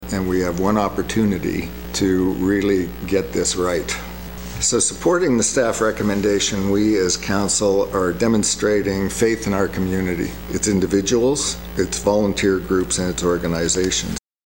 The issue was dealt with at Shire Hall on Thursday during their Committee of the Whole meeting in a packed council chambers and council voted to defer the matter another eight months.
Mayor Steve Ferguson says approving the recommendation shows faith in the community.